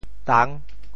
“宕”字用潮州话怎么说？
宕 部首拼音 部首 宀 总笔划 8 部外笔划 5 普通话 dàng 潮州发音 潮州 dang6 文 中文解释 宕 <名> 洞穴;洞屋 [cave] 宕,过也。